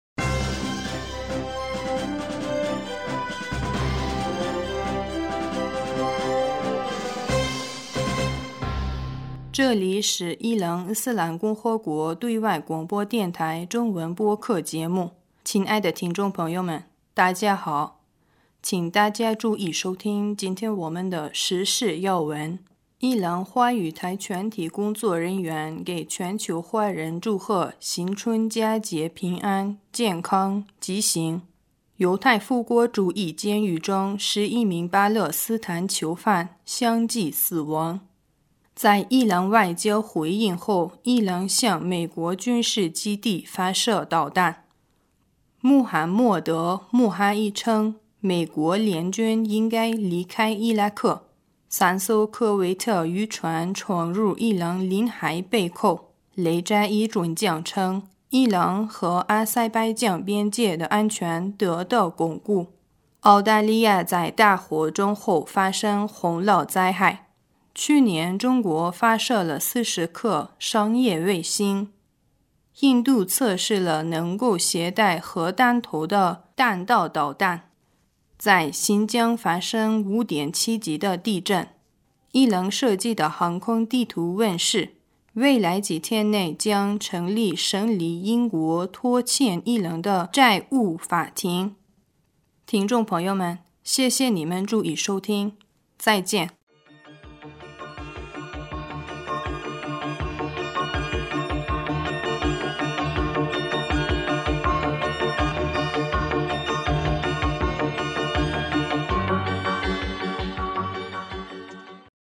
2020年1月20日 新闻